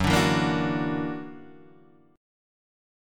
F#+7 chord